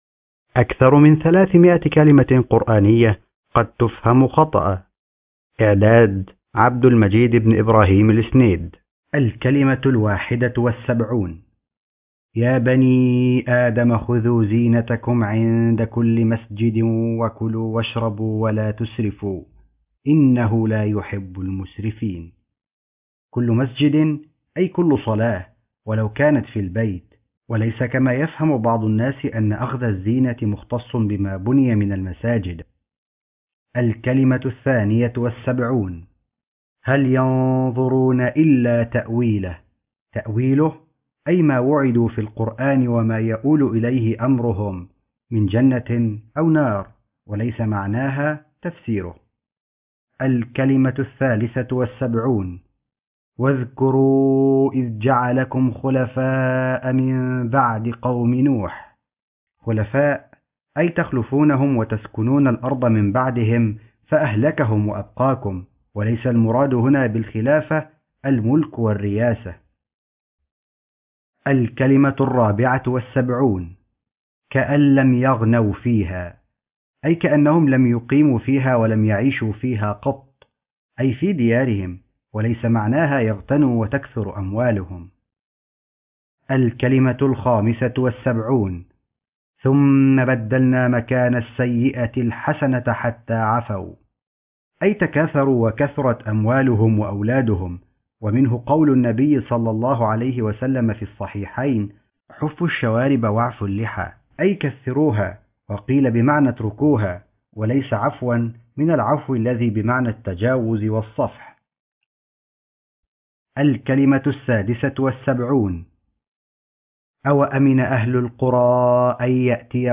أكثر من 300 كلمة قرآنية قد تفهم خطأ ( كتاب صوتي مقروء )